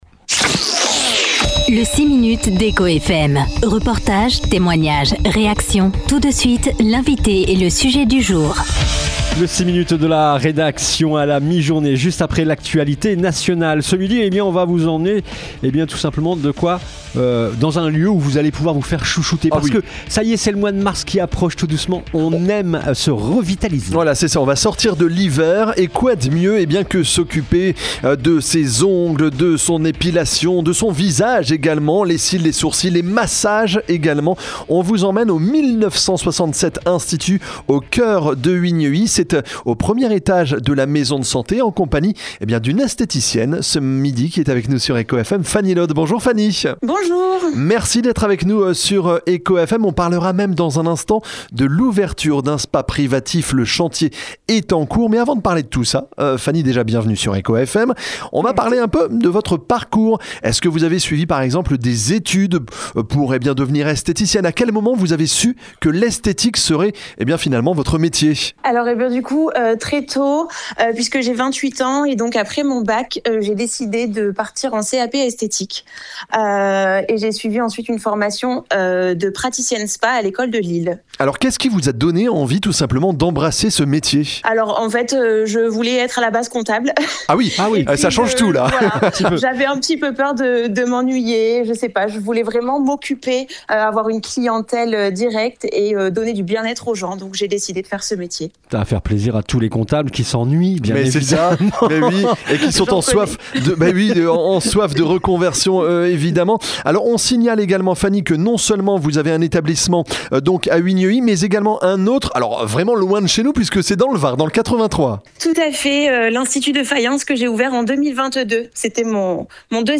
Vous en saurez davantage dans l’interview…